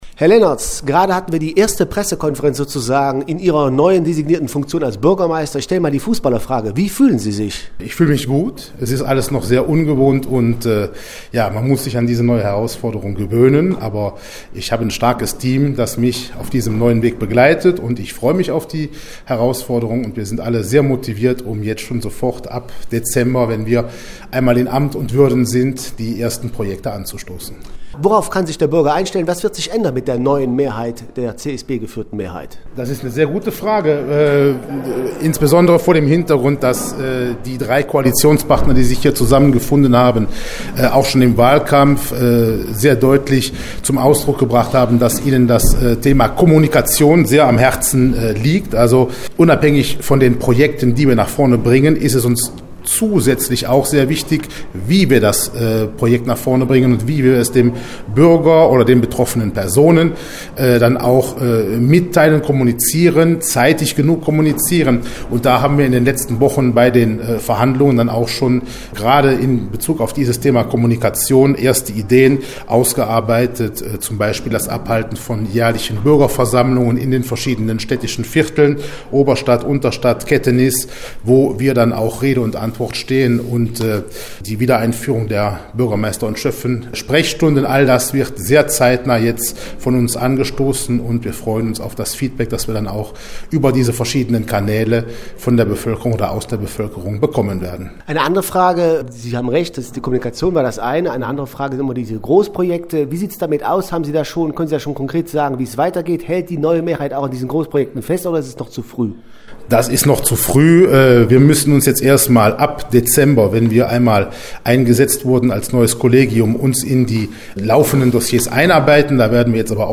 sprach mit dem designierten Bürgermeister Thomas Lennertz.